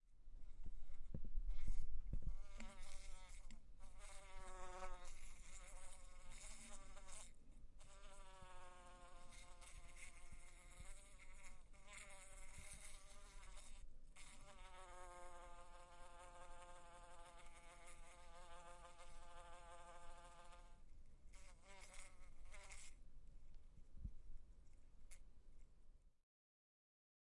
随机 " 荧光灯开关开着关着电嗡嗡声刺耳的关闭1
描述：荧光灯开关电嗡嗡声多刺close1.wav
Tag: 荧光 关闭 开关 嗡嗡声